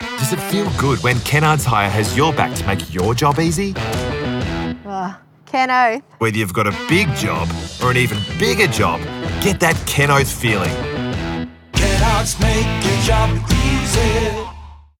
Olá, sou uma dubladora profissional de inglês australiano com uma entrega calorosa, clara e versátil.
Inclui um microfone Rode NT1-A e uma interface de áudio, tudo instalado em uma cabine à prova de som para garantir excelente qualidade de gravação.